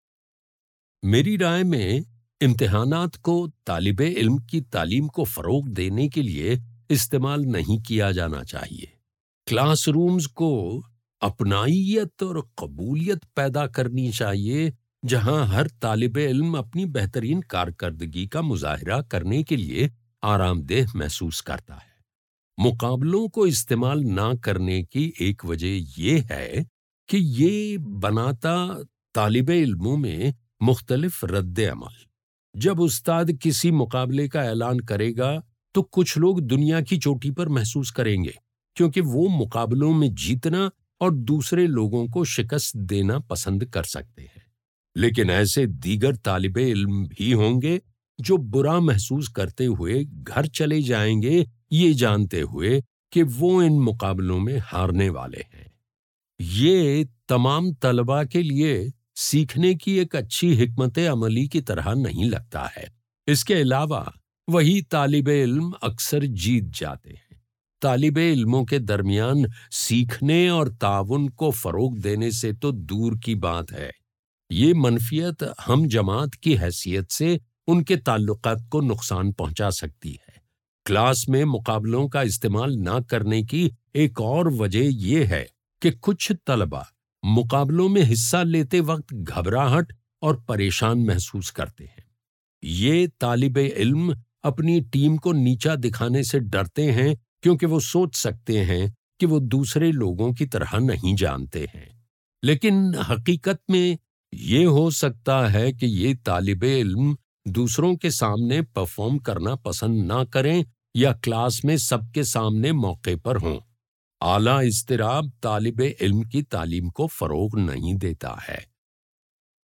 [Note: In the transcript below, ellipses indicate that the speaker paused.]